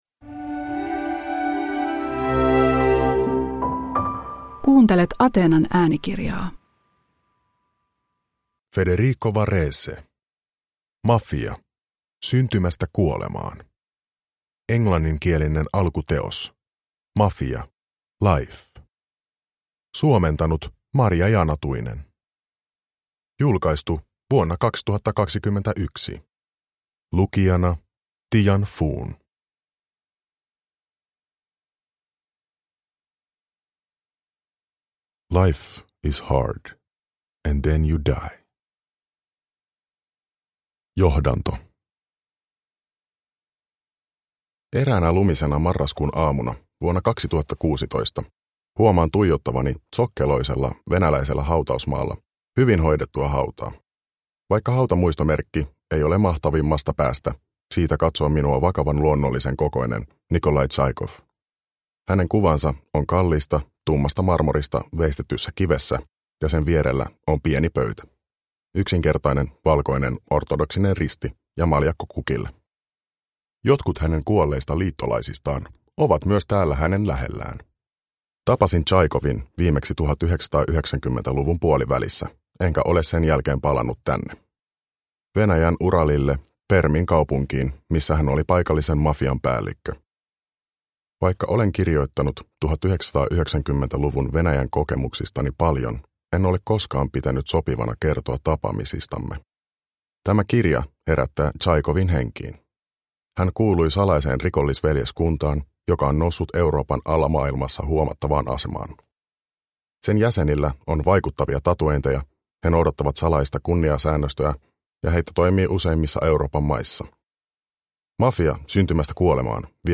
Mafia – Ljudbok – Laddas ner